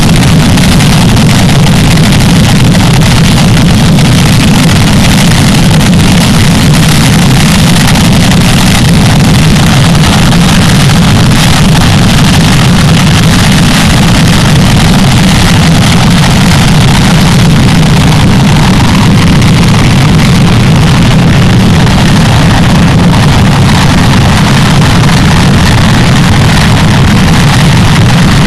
vulcan-jetblast.wav